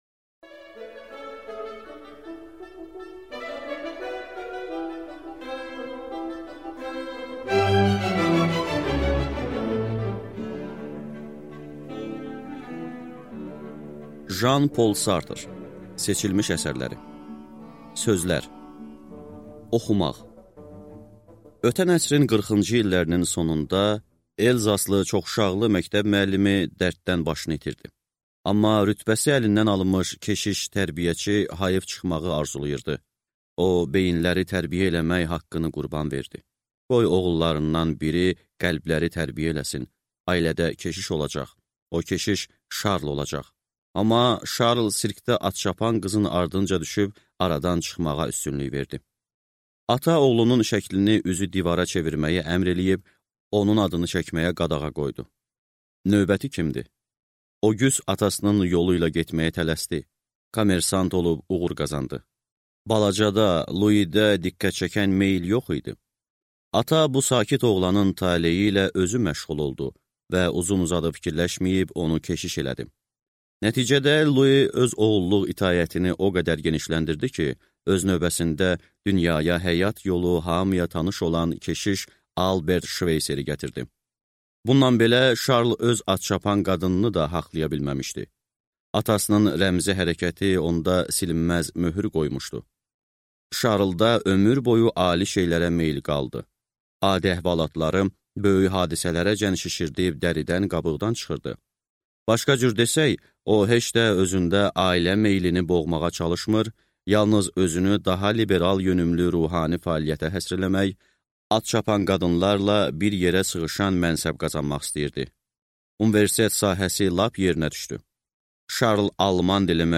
Аудиокнига Seçilmiş əsərləri | Библиотека аудиокниг